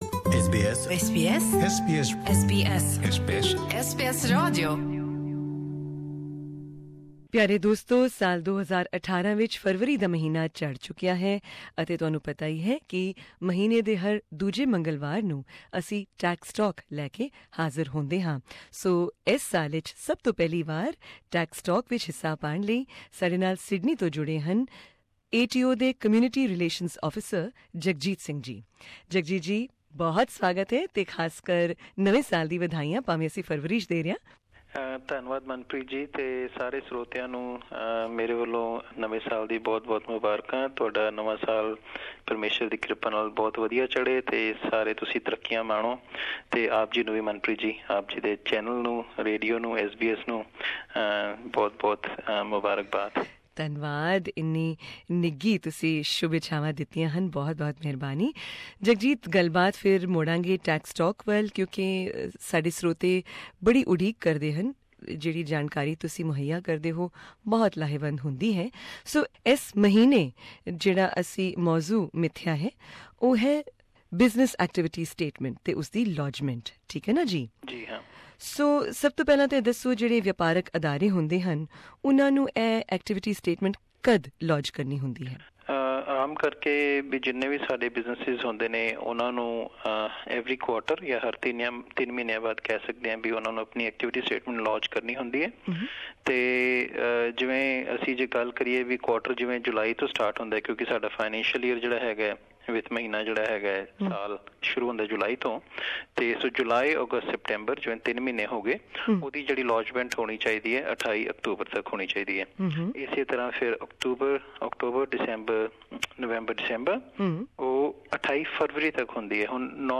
Punjabi Tax Talk is a monthly segment on the radio, brought to you by SBS Radio and ATO Source: SBS Punjabi